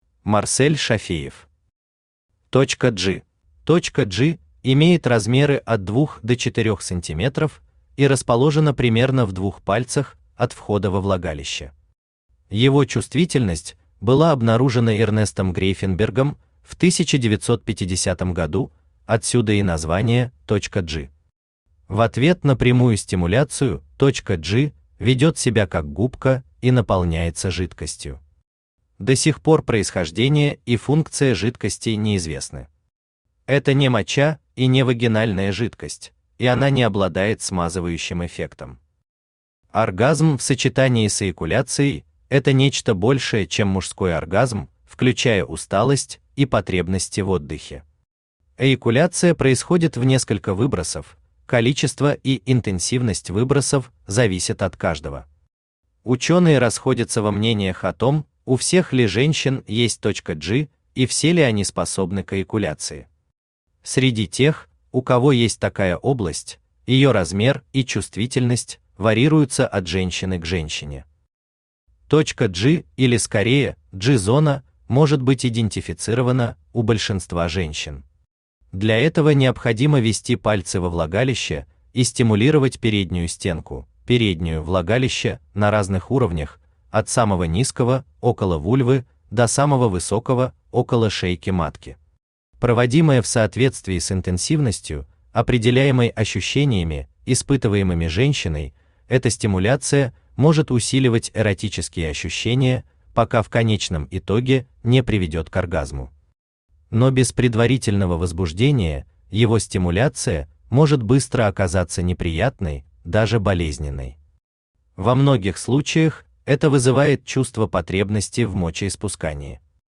Аудиокнига Точка G | Библиотека аудиокниг
Aудиокнига Точка G Автор Марсель Зуфарович Шафеев Читает аудиокнигу Авточтец ЛитРес.